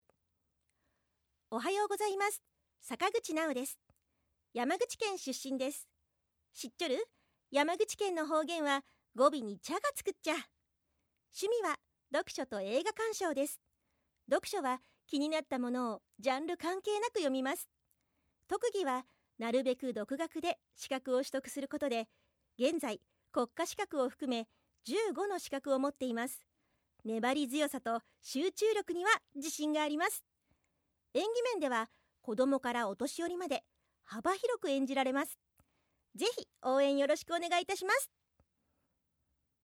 自己PR